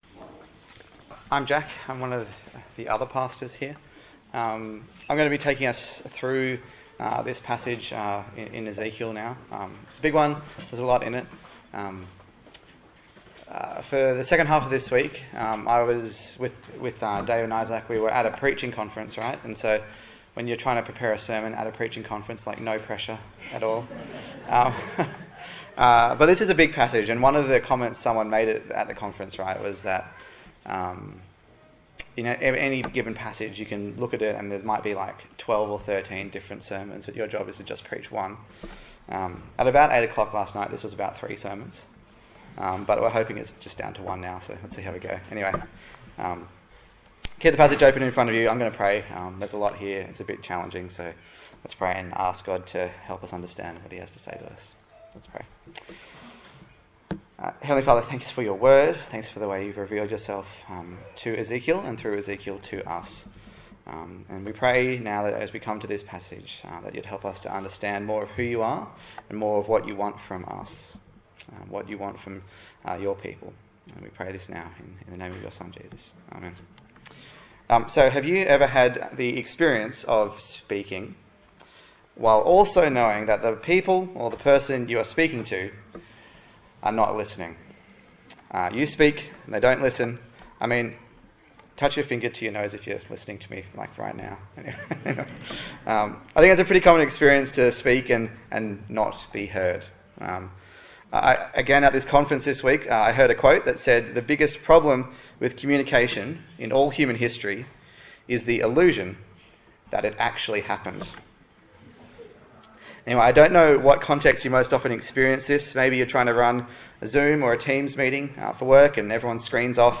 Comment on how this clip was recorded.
Ezekiel Passage: Ezekiel 2, Ezekiel 3 Service Type: Sunday Morning